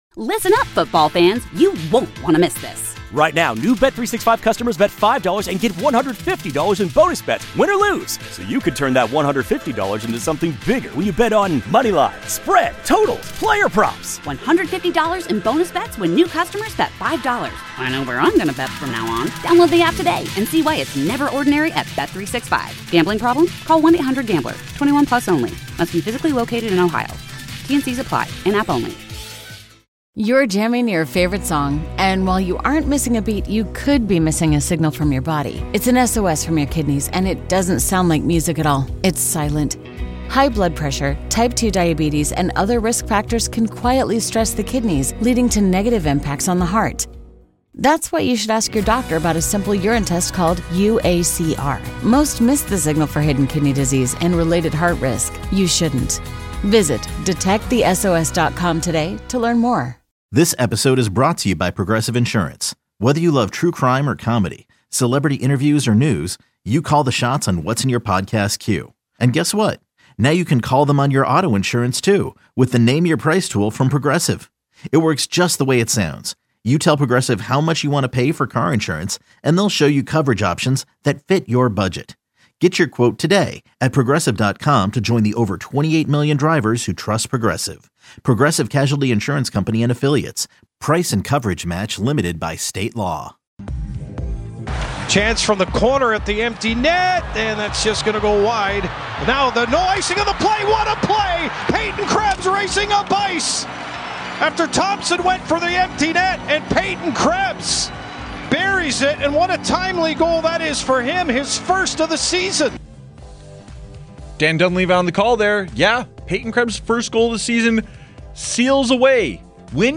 Best Of Sabres on WGR: Heading Into the Break On Fire. 12-24 Full Show.
THE EXTRA POINT is designed for full engagement with bills fans as the two hosts open the phones, texts sand tweets to connect with the Bills mafia on everything Bills!